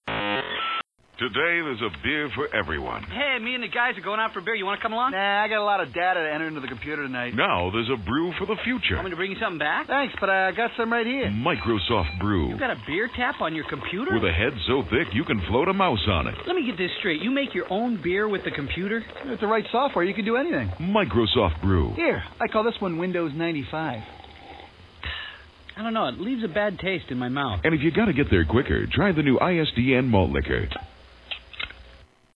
Something like this… notice the modem-like sound at the beginning:
AudioCueCommercial.mp3